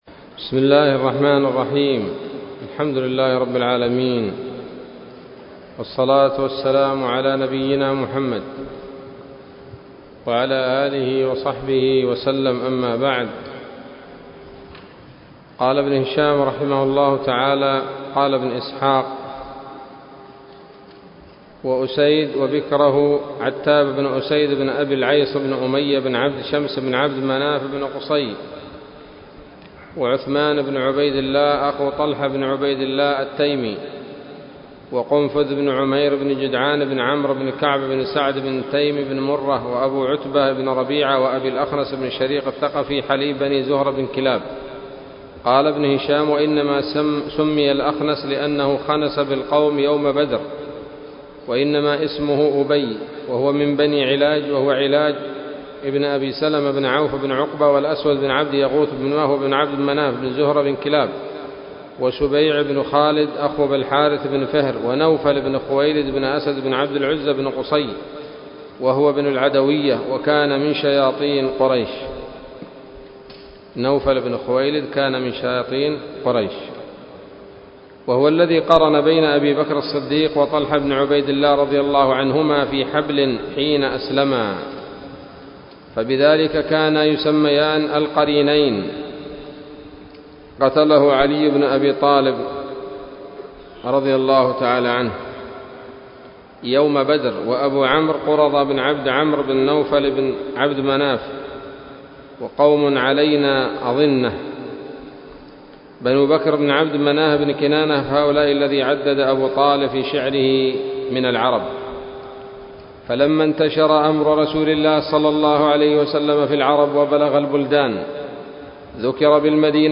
الدرس التاسع والعشرون من التعليق على كتاب السيرة النبوية لابن هشام